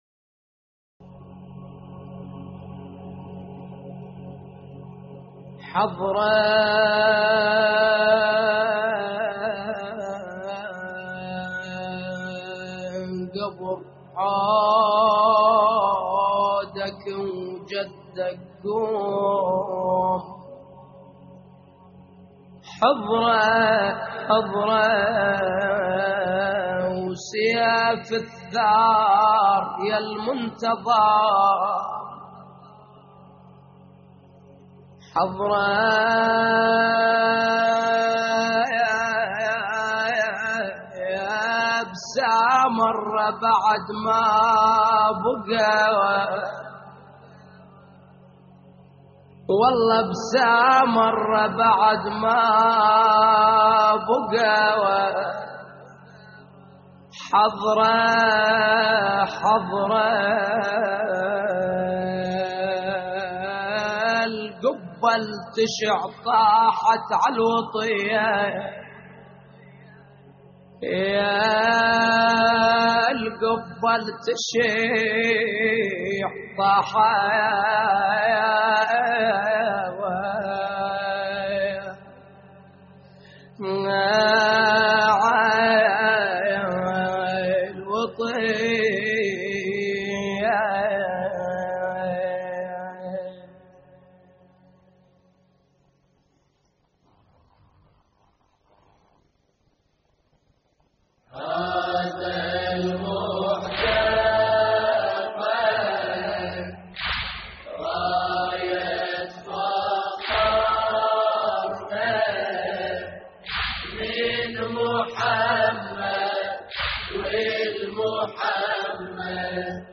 استديو «سامراء»